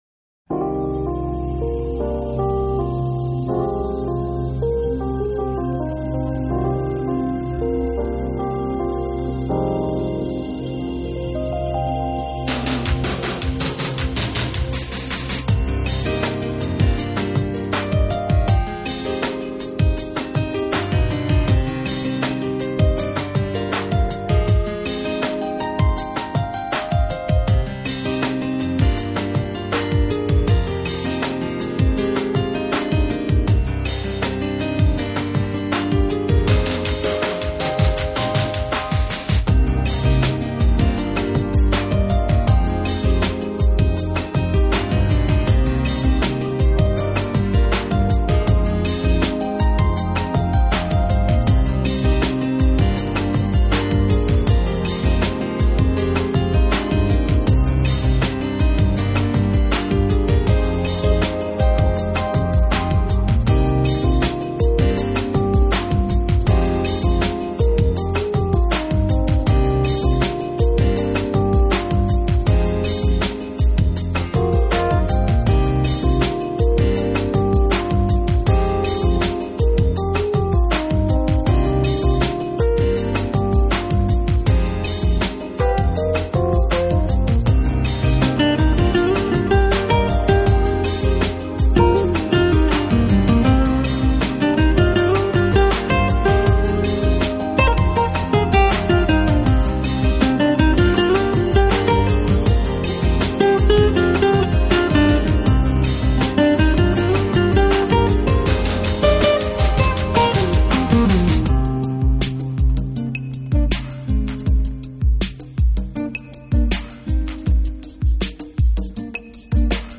专辑风格：Smooth Jazz
在听过这些唱片的人们都会认为他们玩的音乐其实是具有清新风格的电子氛围New Age。